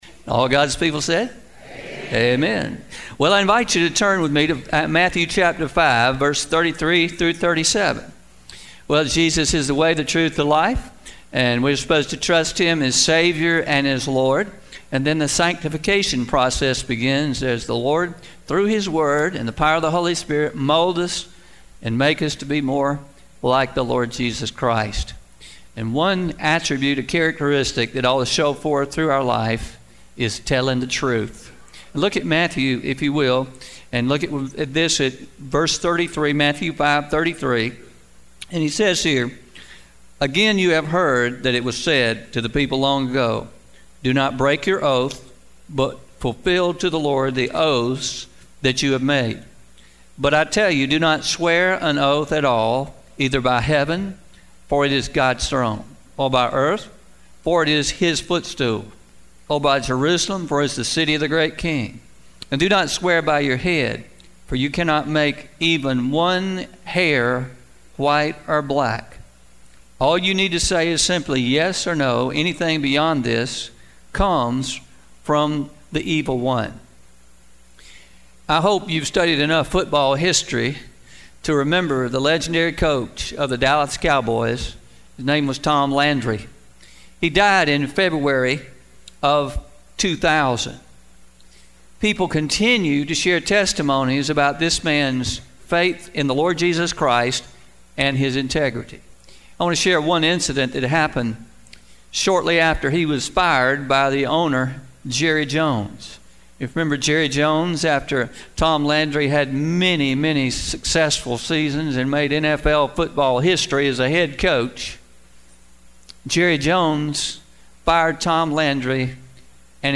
Truth Telling 101 (Pre-Recorded)